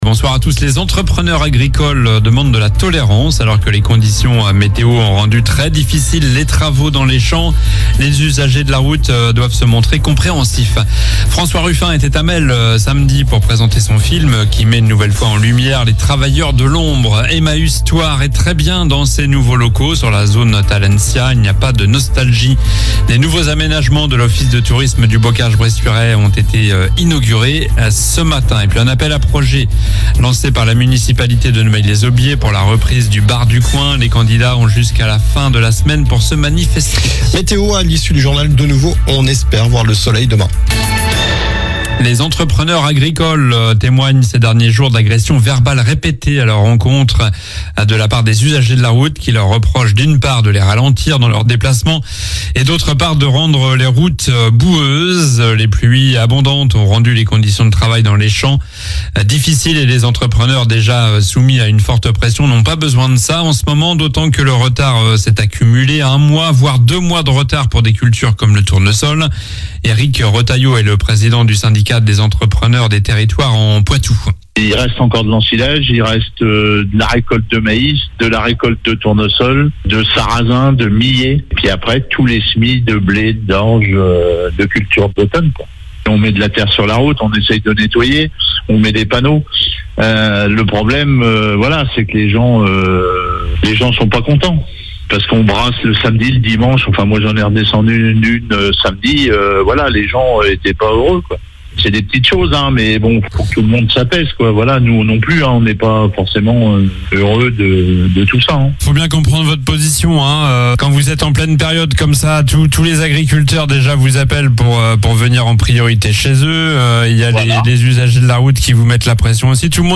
Journal du lundi 4 novembre (soir)